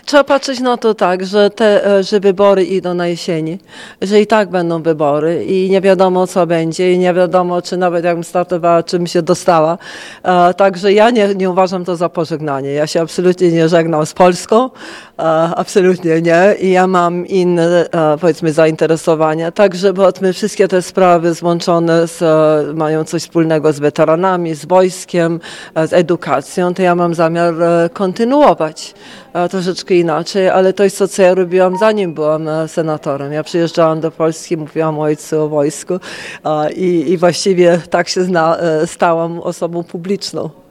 – To nie jest pożegnanie – mówiła w Ełku senator Anna Maria Anders.